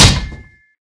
Sound / Minecraft / mob / zombie / metal3.ogg
metal3.ogg